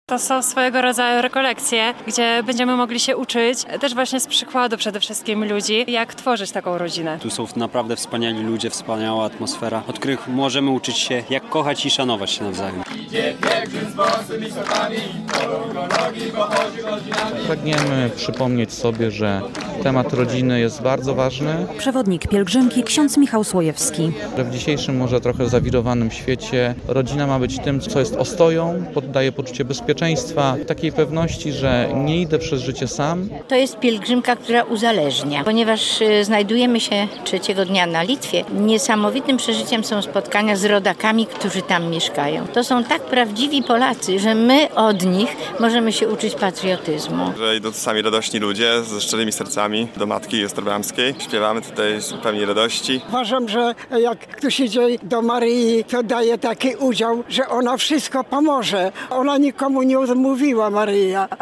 Wyruszyła 34. pielgrzymka z Suwałk do Wilna - relacja